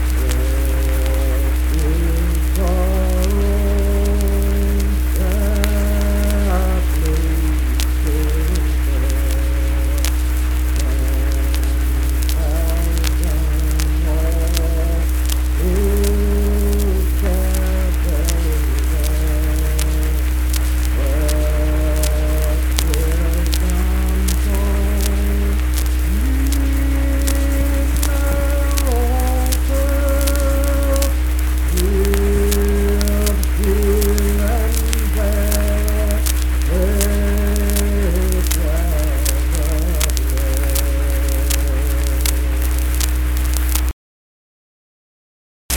Unaccompanied vocal music
Hymns and Spiritual Music
Voice (sung)
Webster County (W. Va.)